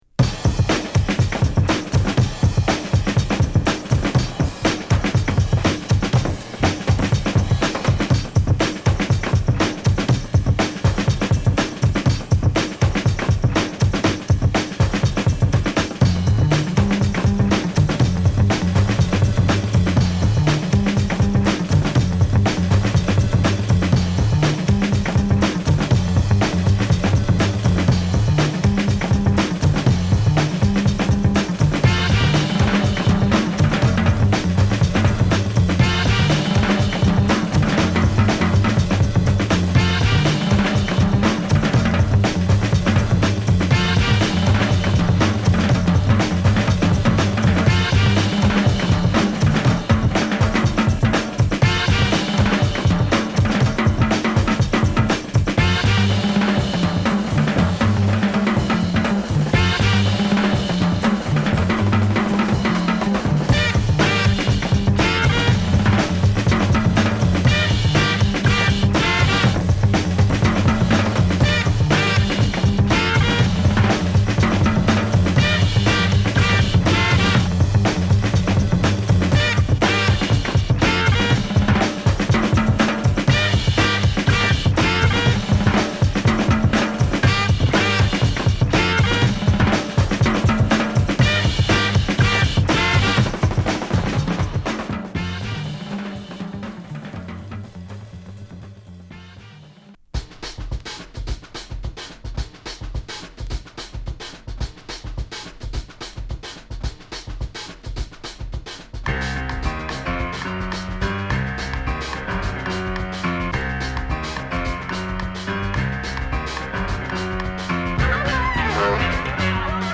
SideAは、打ちまくりのドラムにヘヴィーなベースライン、キレの良いホーンを絡めたストレートなファンクチューン！